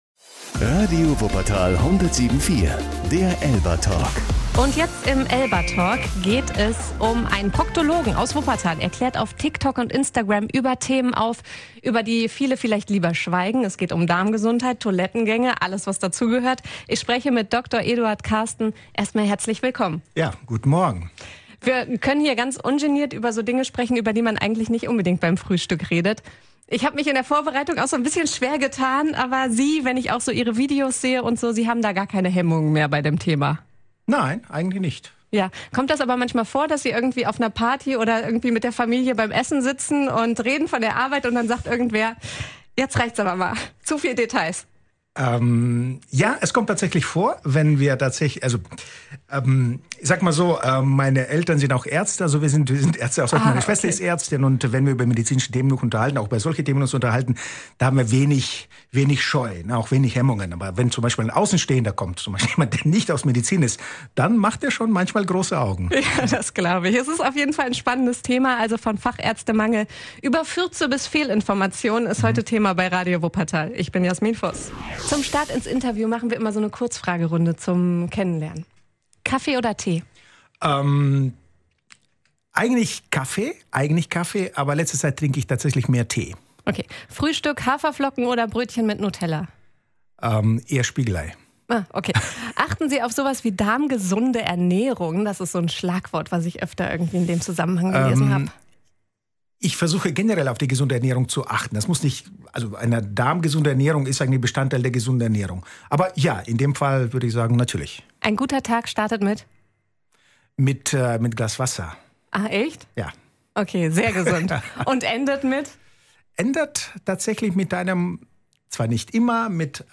Wir sprechen über Hemmungen, typische Beschwerden und hartnäckige Irrtümer, über Social Media und Fachärztemangel. Hört euch das ganze Interview hier an.